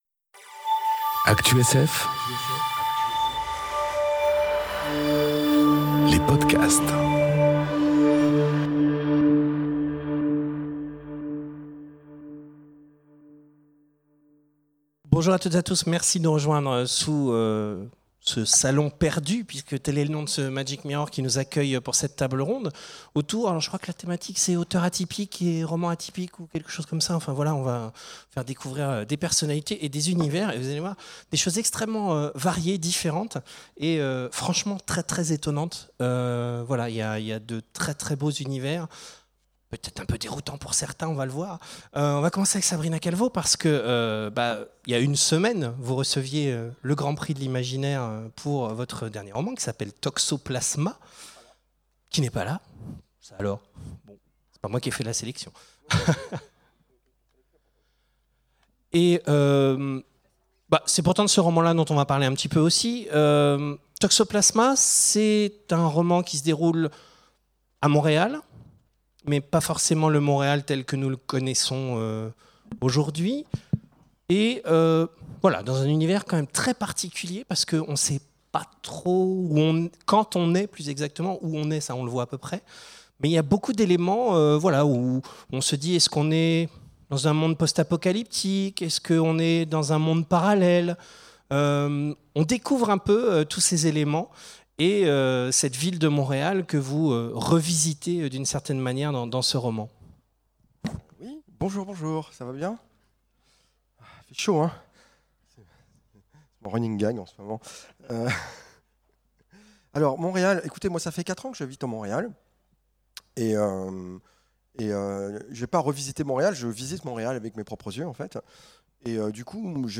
Conférence Auteurs et romans atypiques... Ah, les belles découvertes ! enregistrée aux Imaginales 2018